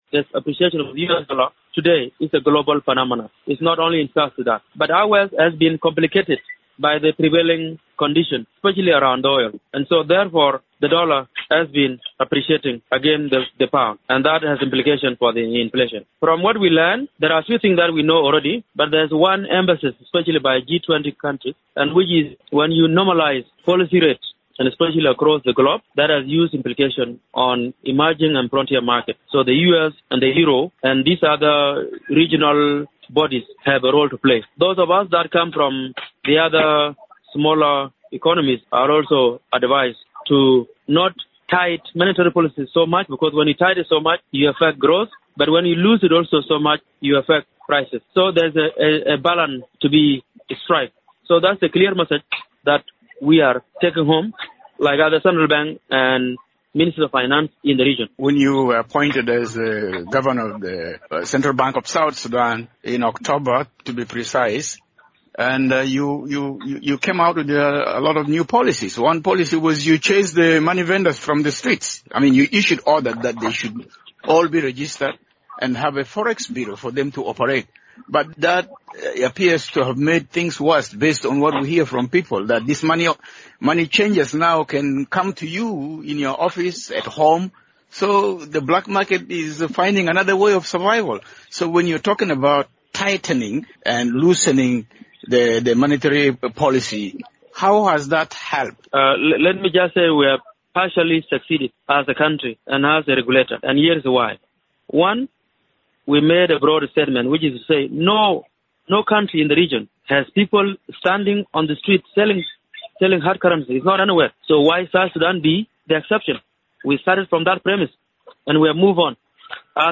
South Sudanese authorities speak to VOA about World Bank and IMF Spring meetings